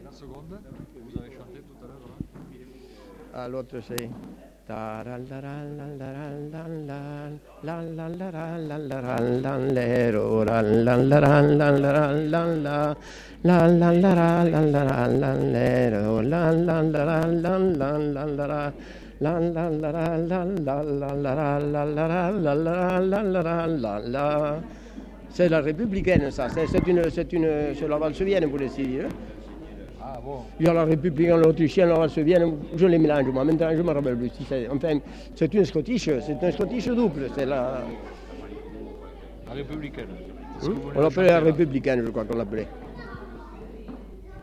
Républicaine (fredonné) Centre culturel.